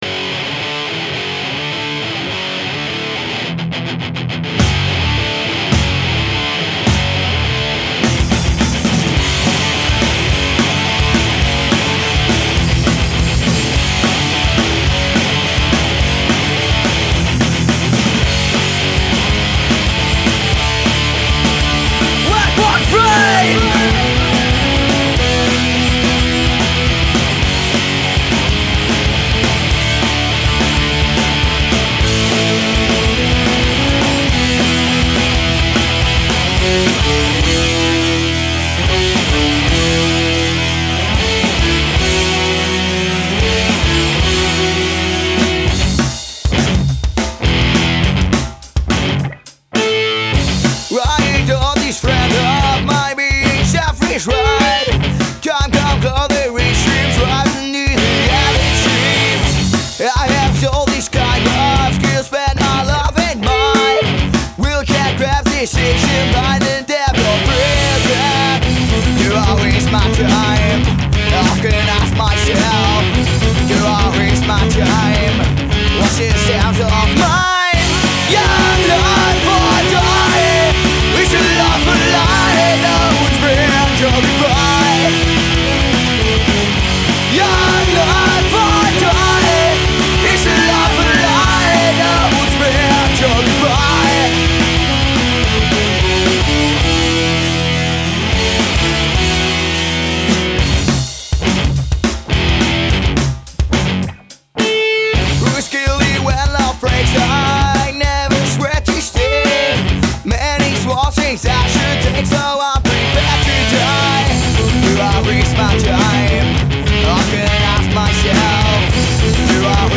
Alternative Rock, Nu Metal, Emocore